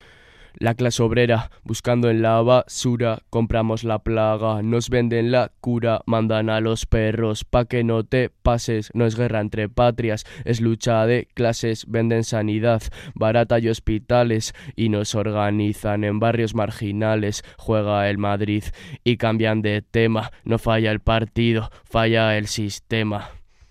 Disfruta con esta poesía, perteneciente al festival 'Poetas en Mayo', que se lleva a cabo en Vitoria-Gasteiz durante el mes de mayo de 2018. Poema del festival Poetas en Mayo, de cultura de Gasteiz, en Radio Vitoria.